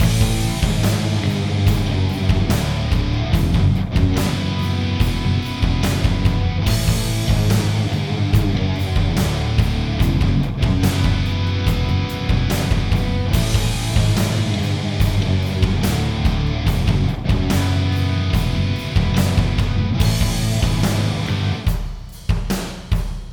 Here's my first recording test/experimentation.
Attachments Guitar Tone Test in F#m.mp3 Guitar Tone Test in F#m.mp3 731.5 KB · Views: 355